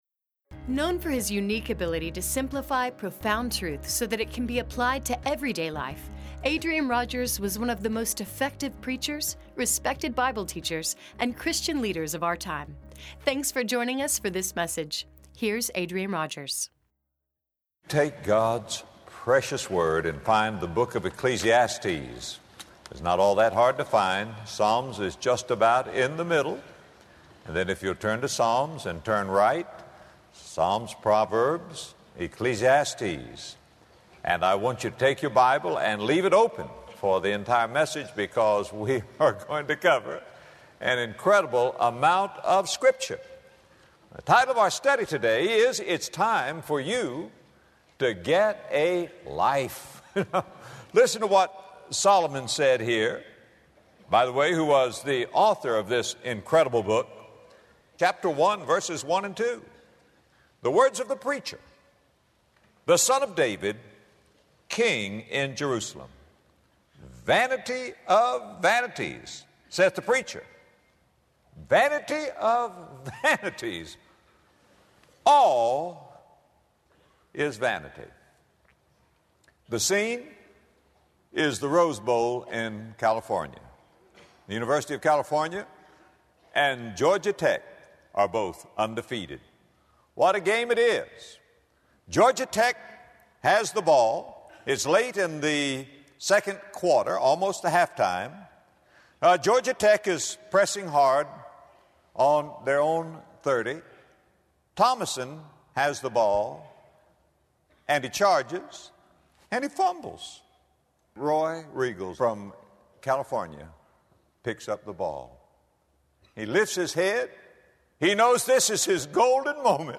In this message, Adrian Rogers explores three themes from this book when considering the beauty of this life we get to live.